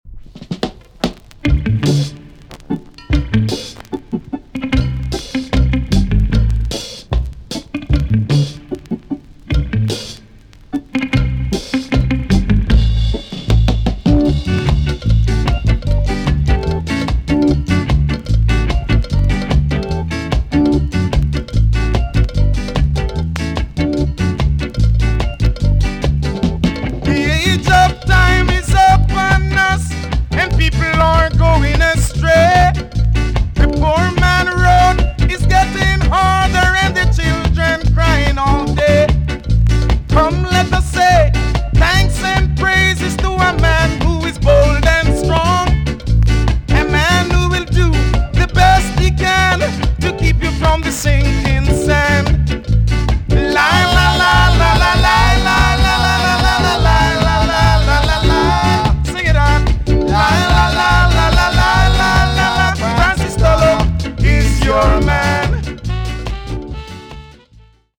TOP >REGGAE & ROOTS
EX-~VG+ 少し軽いチリノイズがありますが良好です。
NICE VOCAL TUNE!!